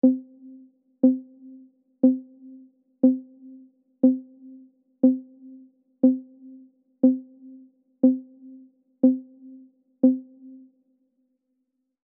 Звуки обратного отсчета в виде сигналов и пиков
11 ударов сухих, без реверберации